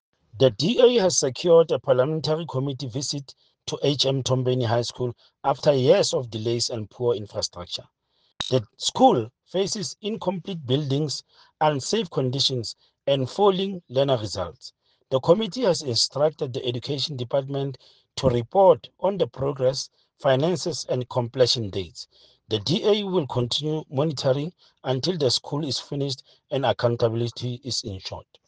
Sesotho soundbites by Cllr Stone Makhema.